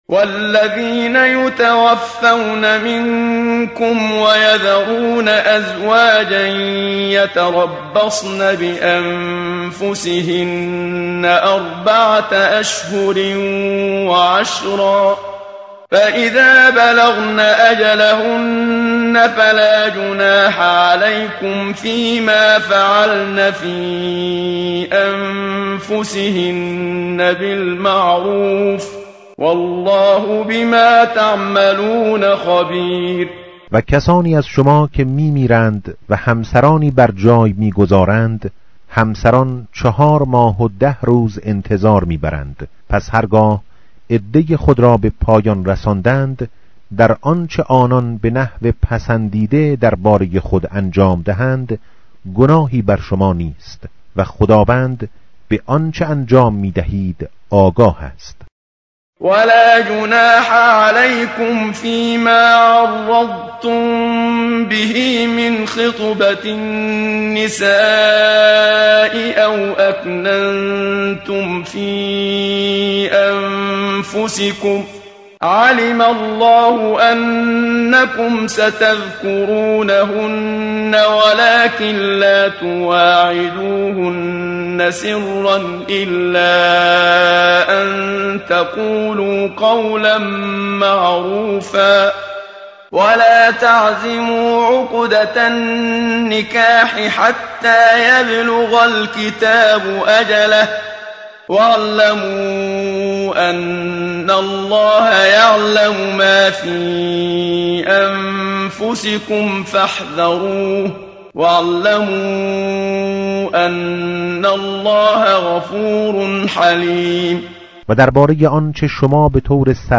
tartil_menshavi va tarjome_Page_038.mp3